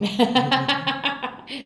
LAUGH 3.wav